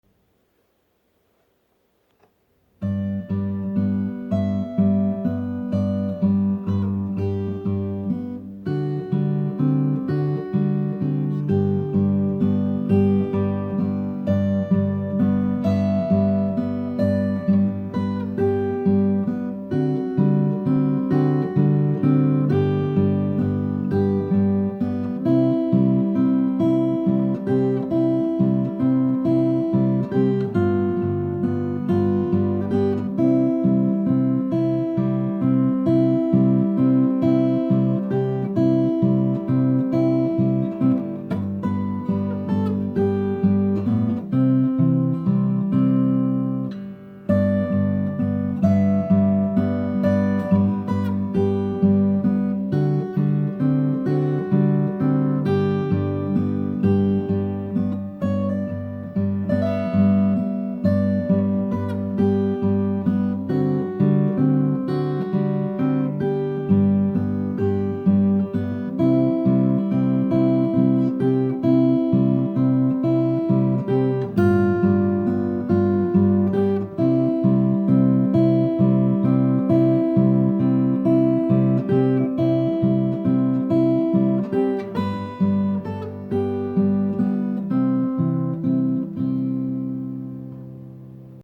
originally from Humboldt Sask. but now living in Saskatoon posted a guitar solo to the YouTube  social media platform.
composed the instrumental tune on the family guitar as a young man.